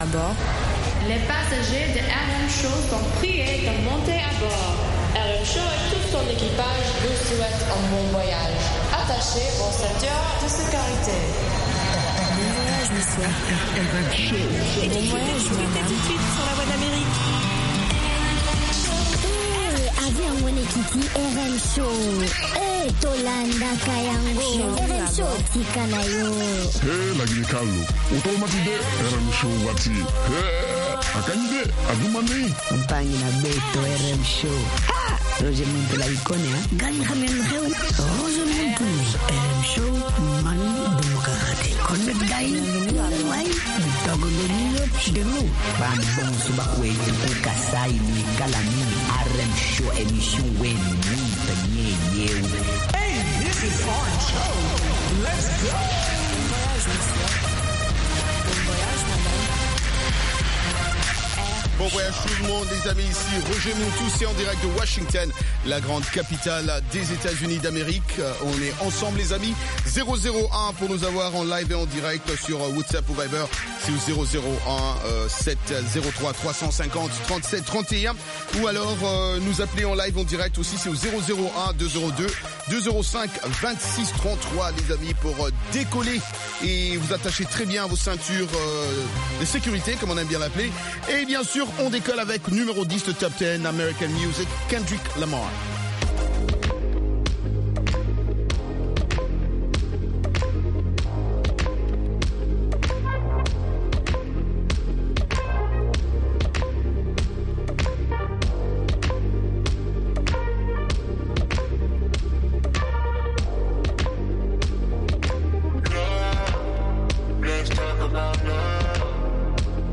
RM Show - Le hit-parade Americain